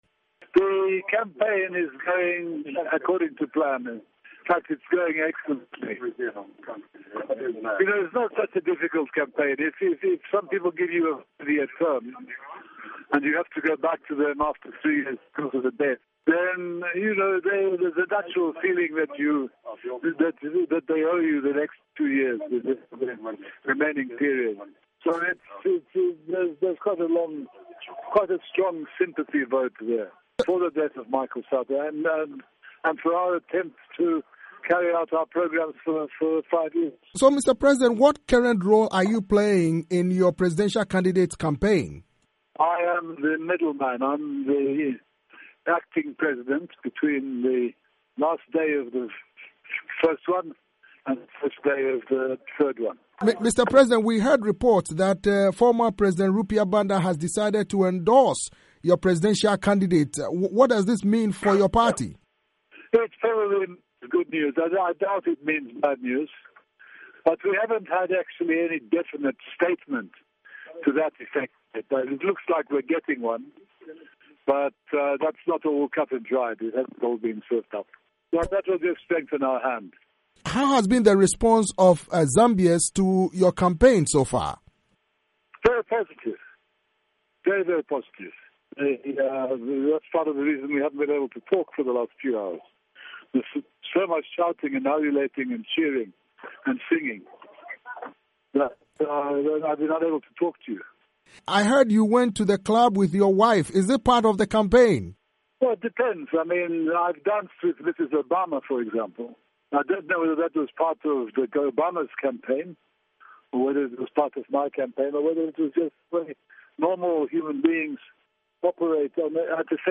In an interview with VOA, Guy Scott dismissed opposition accusations that the PF has implemented schemes, including plans to use state security officials, to rig the January 20 vote.